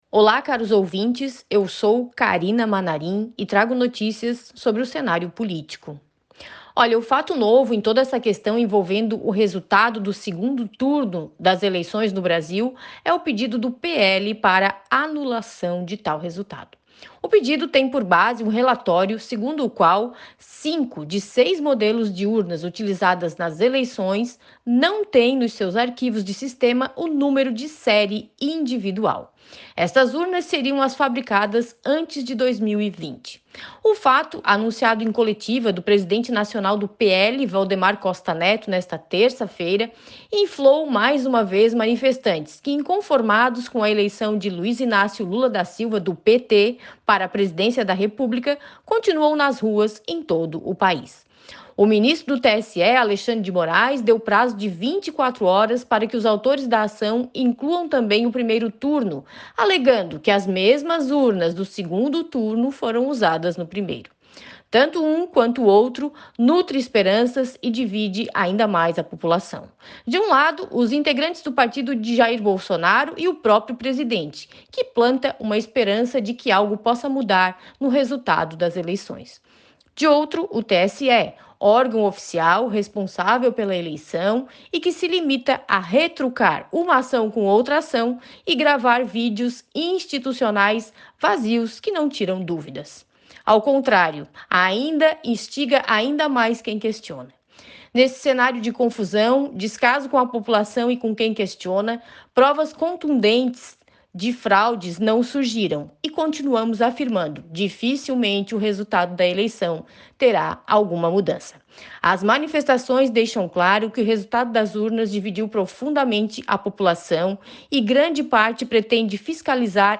Jornalista fala sobre a suposta irregularidade identificada em um dos arquivos gerados, cuja função é associar os votos à urna onde foram registrados